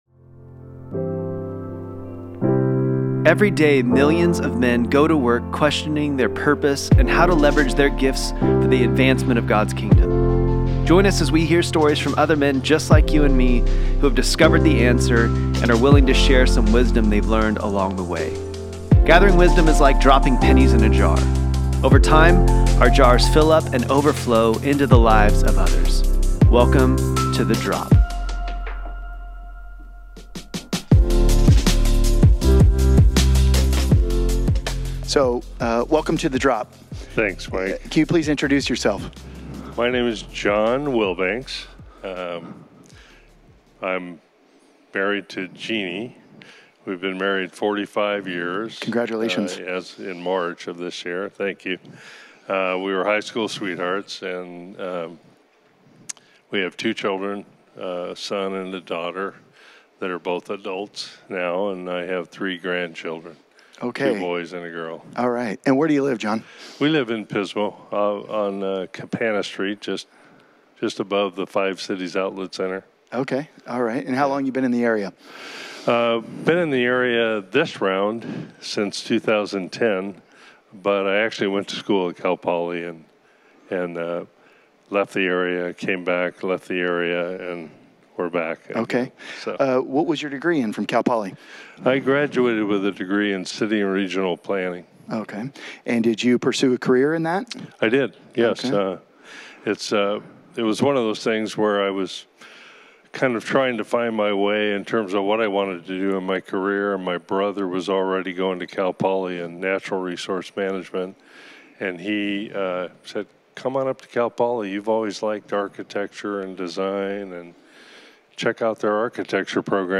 The drop is a podcast featuring conversations with men just like you and me who have discovered how to leverage their gifts for the advancement of God’s kingdom. Our hope is that these stories inspire and motivate you to discover how God can use you within your own area of influence.